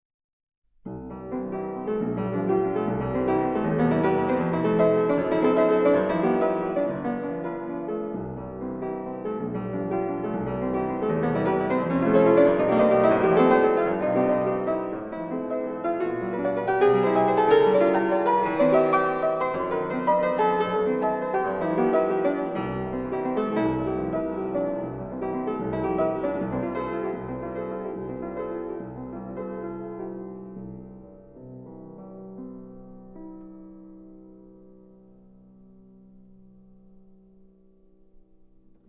Presto con leggierezza